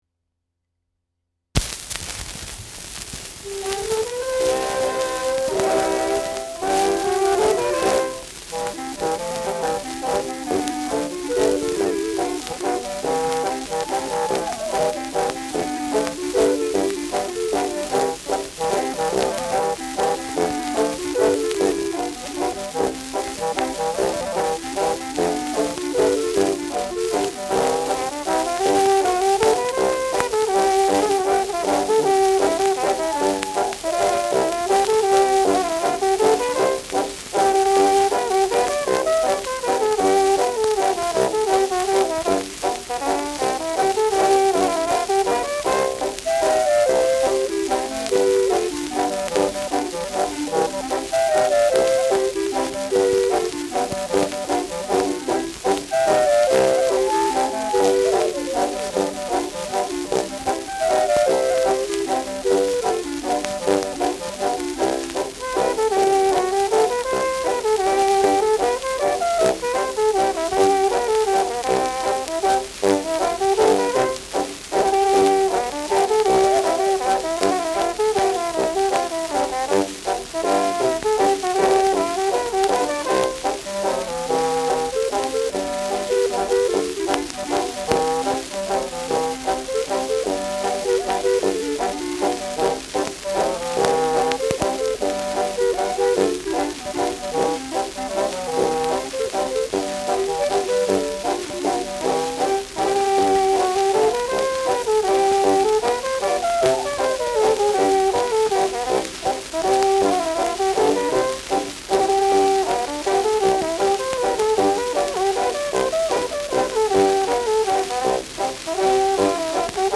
Schellackplatte
Tonrille: leichter Abrieb